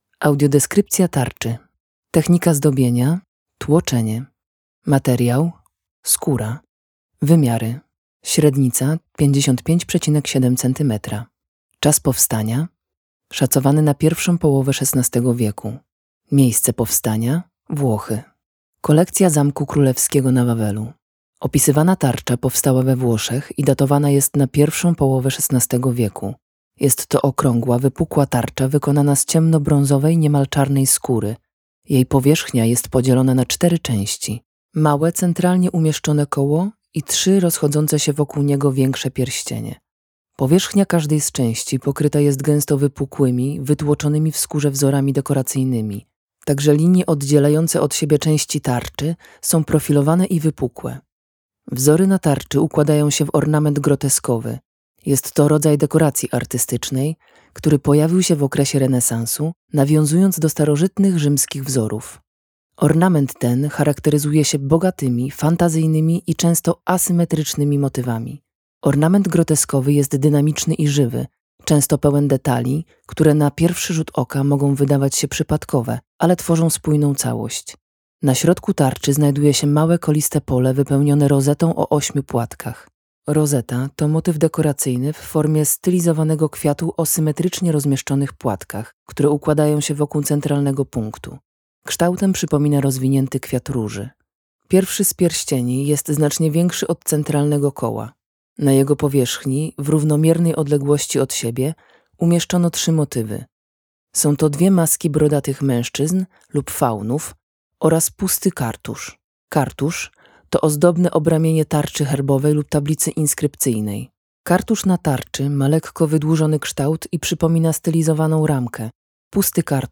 tarcza-aleksandra-audiodeskrypcja.mp3